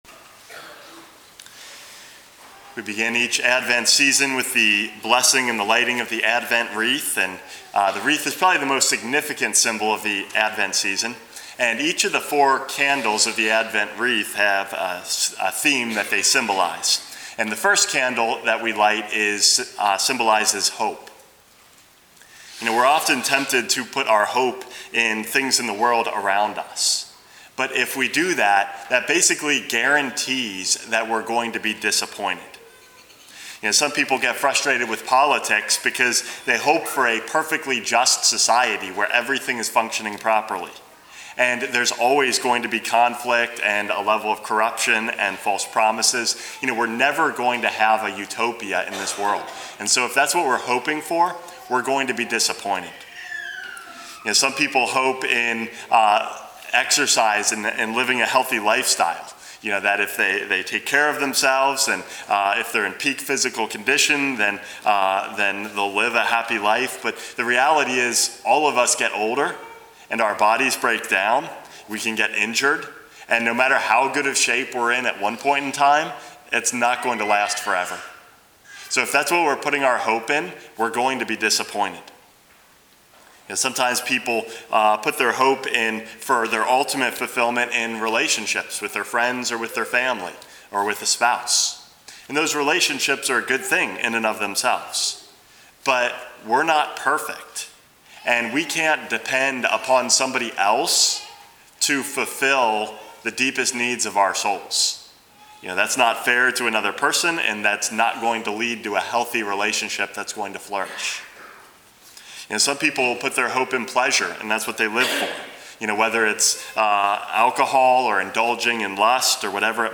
Homily #426 - The First Candle